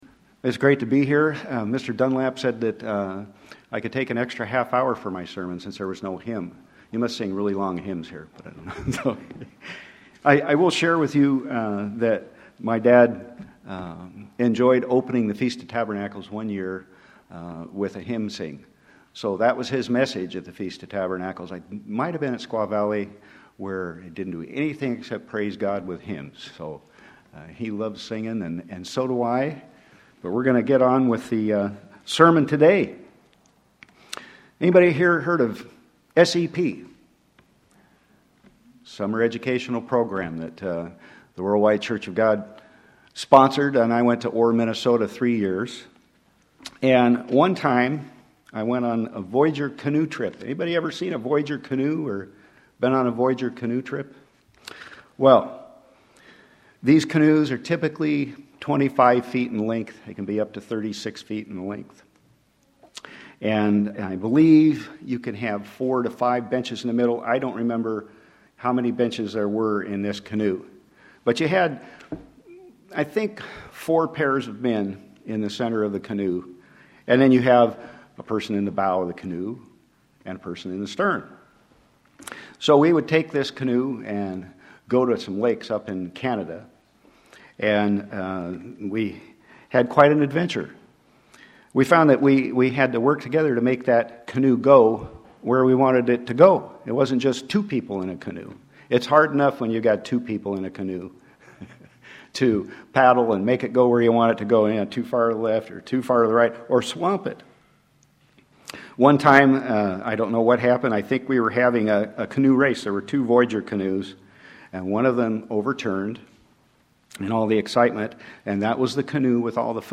This sermon ties some spiritual analogies from the Bible to this story.
Given in Sacramento, CA